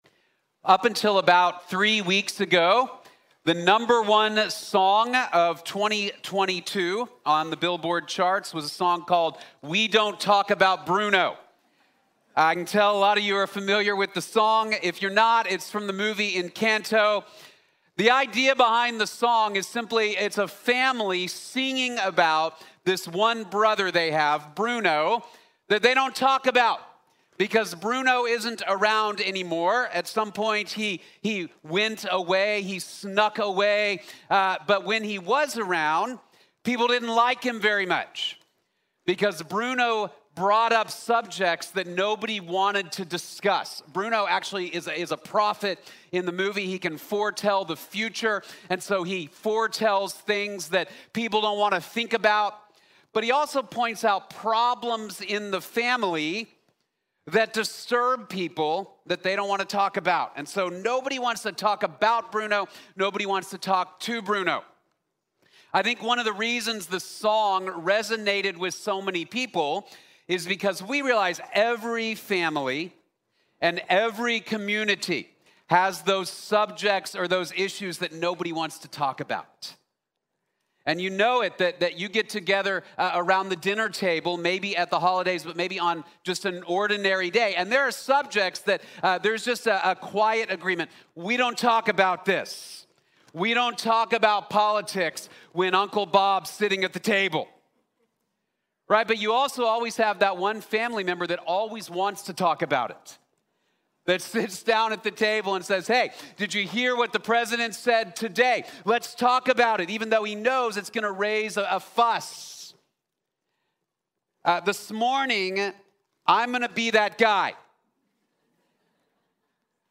We Work for Jesus | Sermon | Grace Bible Church